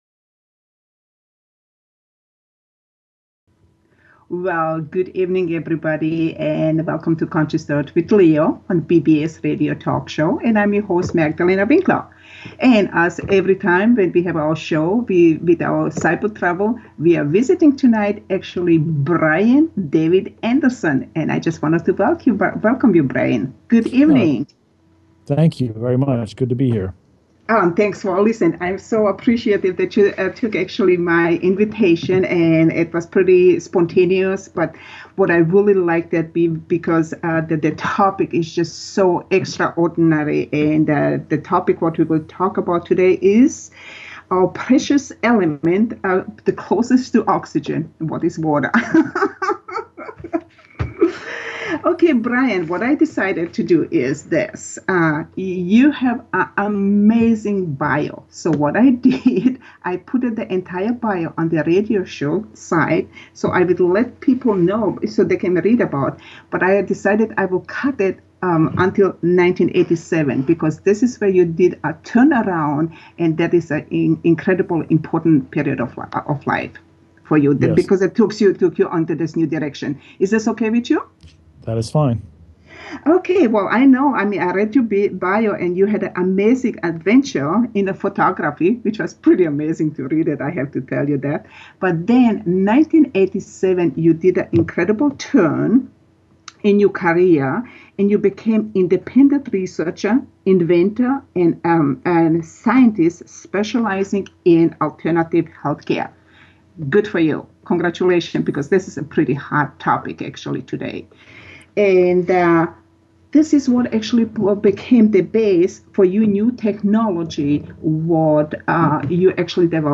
Talk Show Episode
Conversation with Independent Researcher, Inventor, and Scientist specializing in Alternative Health Care.